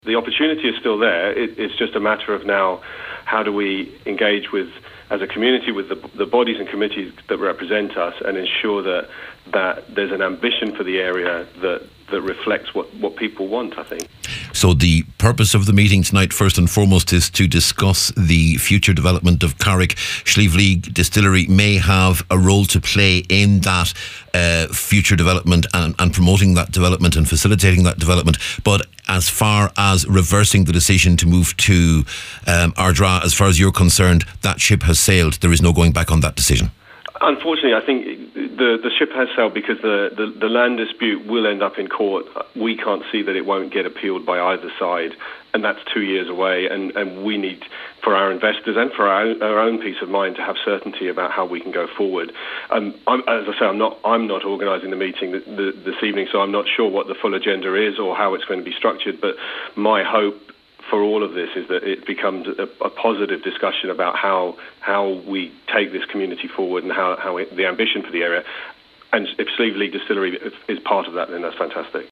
was speaking on today’s Nine til Noon Show.